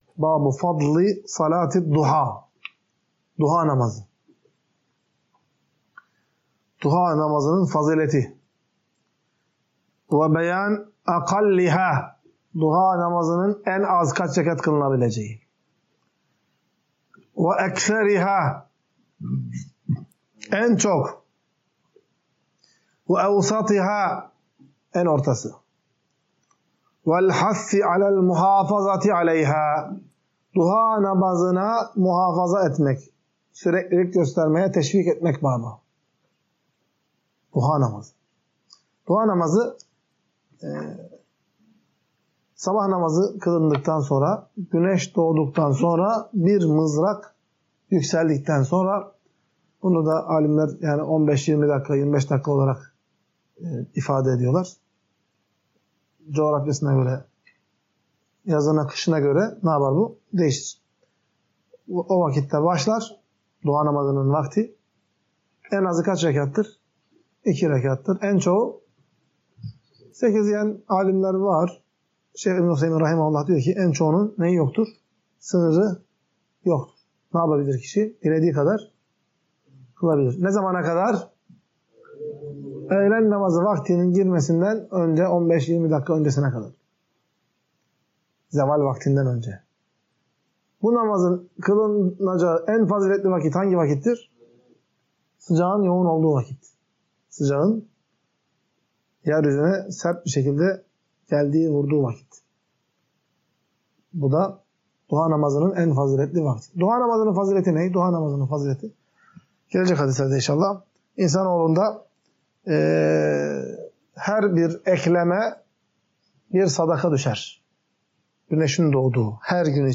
Ders - 27.